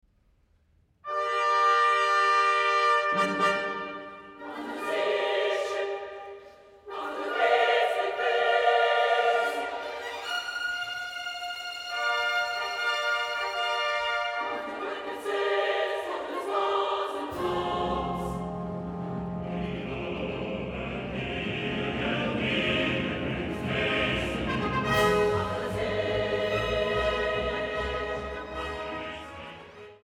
Sopran
Bariton
Largo sostenuto